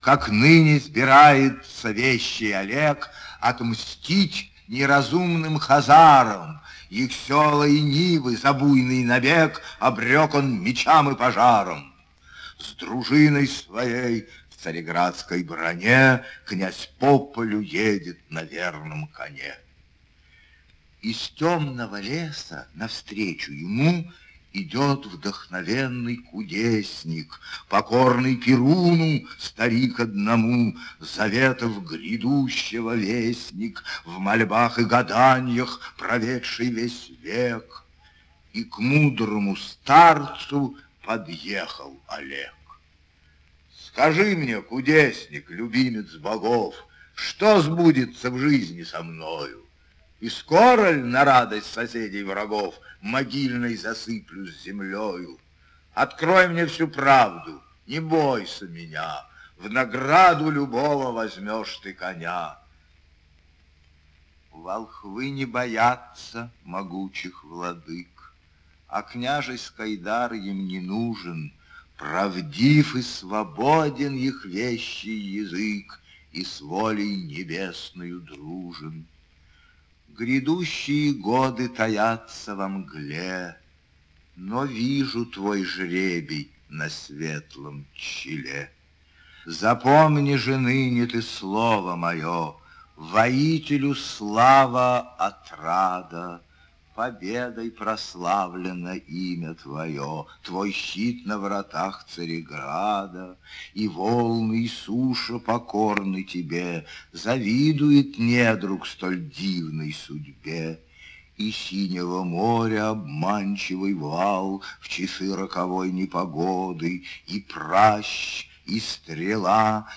Чтение "Песни о Вещем Олеге" в исполнении Д. Журавлева и учителя.